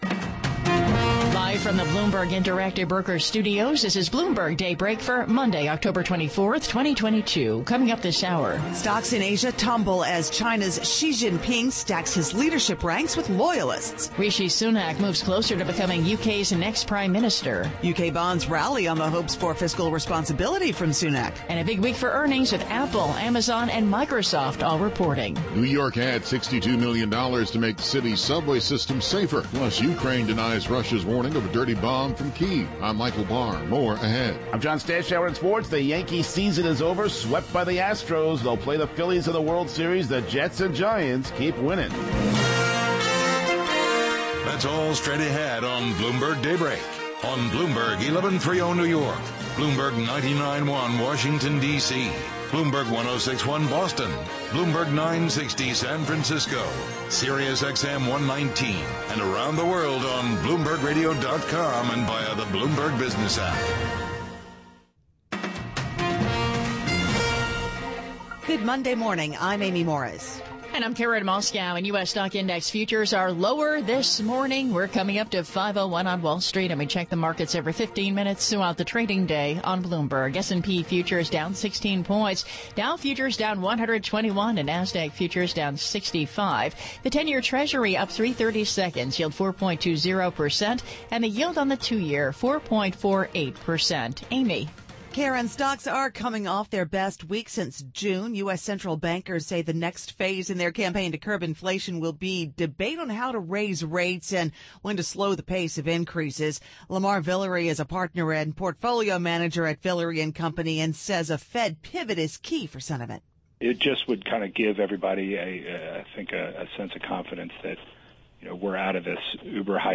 Bloomberg Daybreak: October 24, 2022 - Hour 1 (Radio)